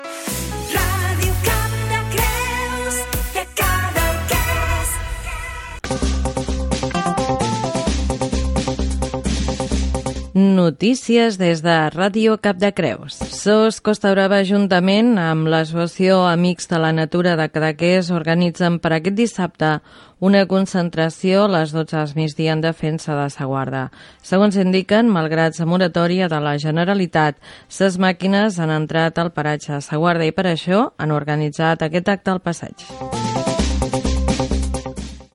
Indicatiu i inici de l'informatiu: SOS Costa Brava convoca una concentració en defensa de Sa Guarda.
Informatiu